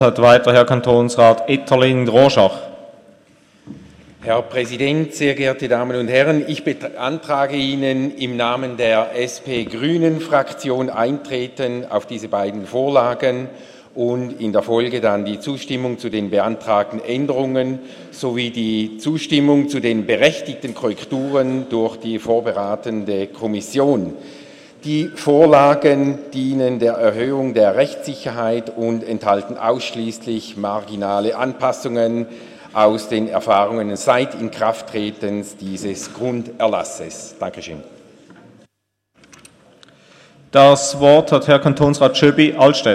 20.9.2017Wortmeldung
Session des Kantonsrates vom 18. bis 20. September 2017